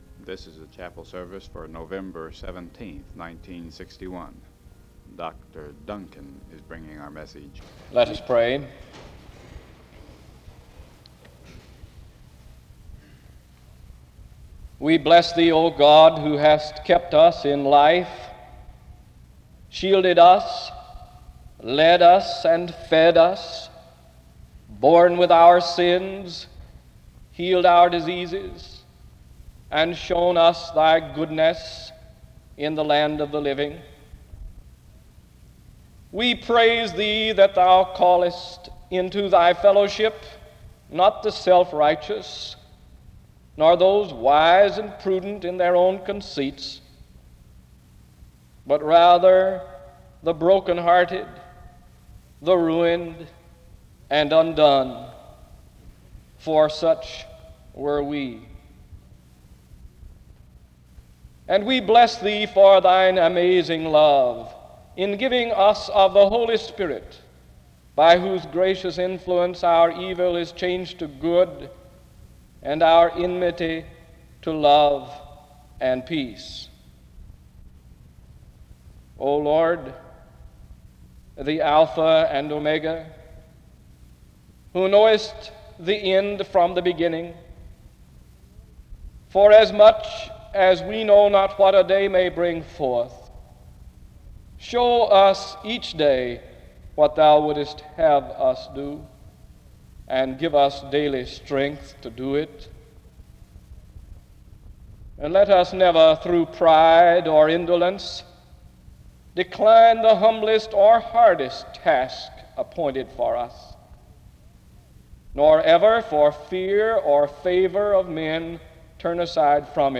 He ends his time with an illustrative story on forgiveness (11:06-14:41) before closing in prayer (14:42-15:10).